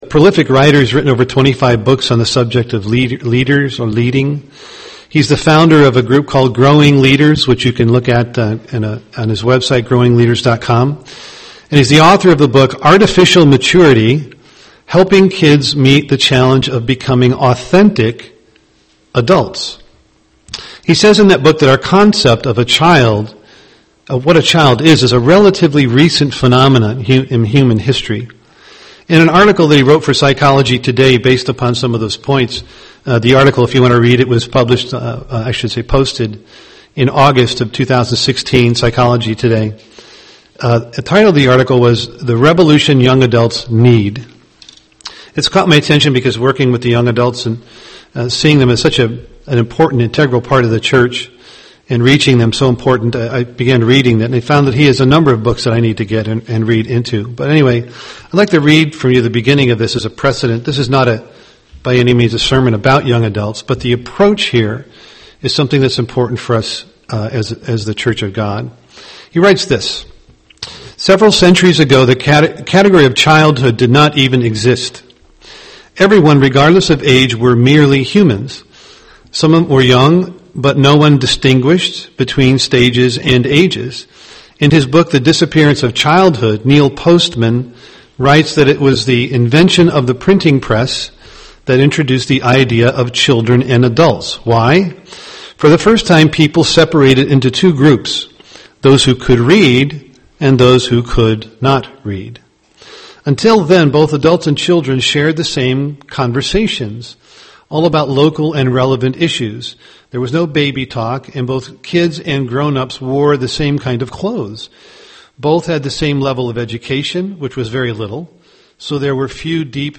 UCG Sermon Christian character maturing Studying the bible?